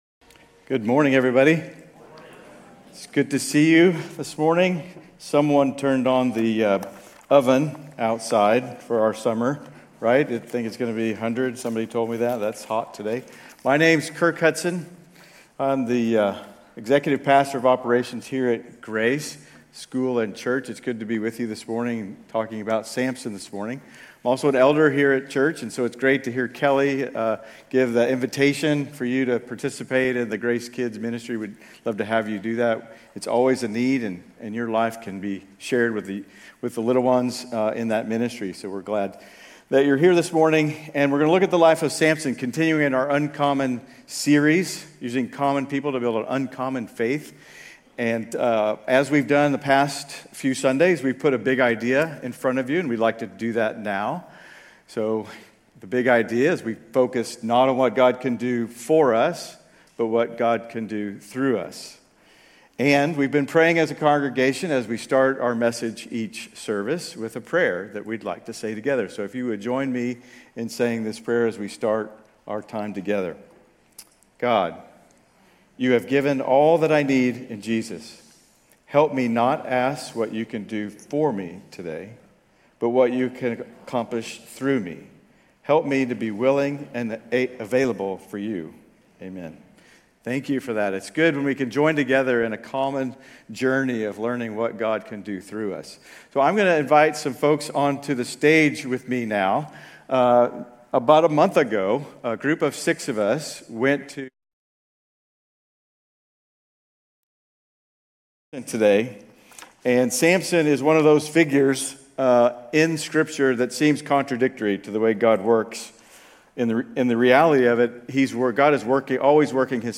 Grace Community Church University Blvd Campus Sermons 7_20 University Blvd Campus Jul 21 2025 | 00:24:39 1x 00:00 / 00:24:39 Subscribe Share RSS Feed Share Link Embed